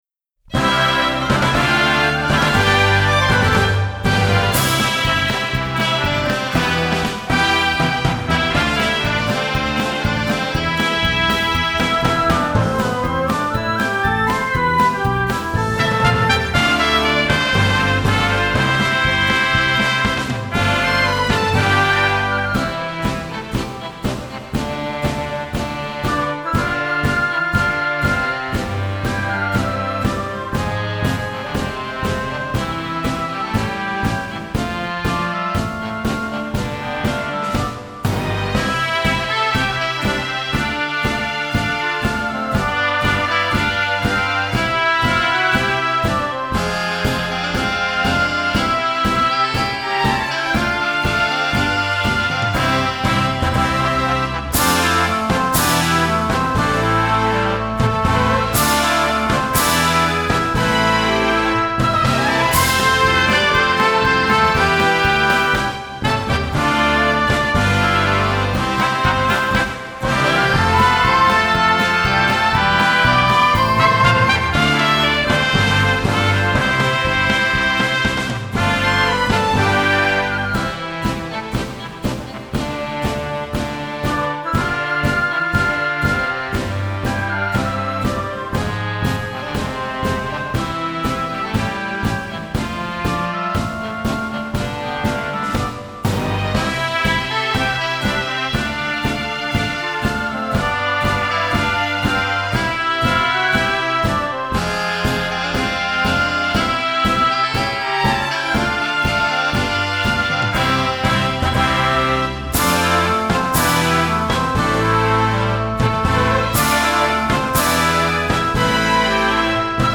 4．コーラスVer.